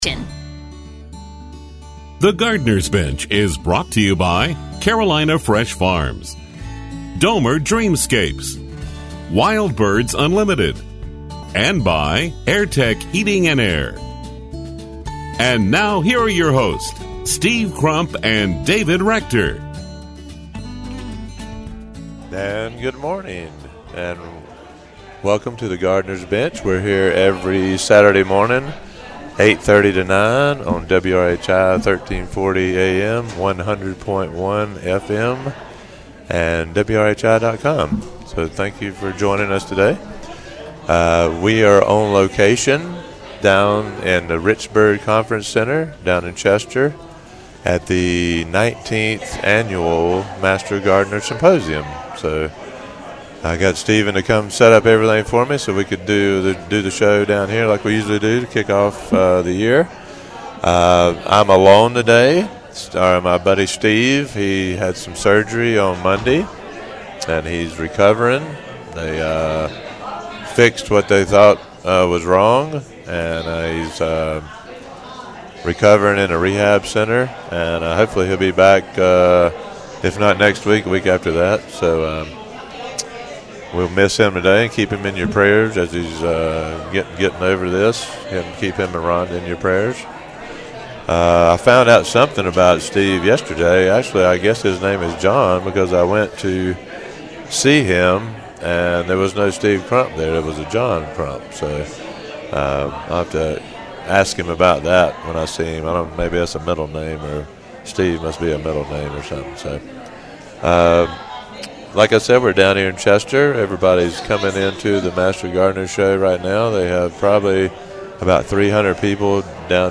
The Gardener’s Bench: 02/22/25 Live From the 19th Annual Joy of Gardening Symposium